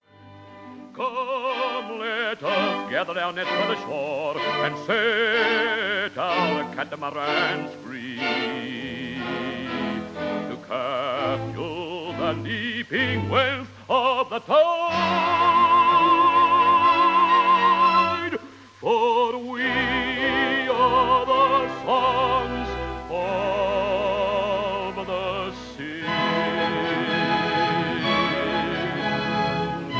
Australian baritone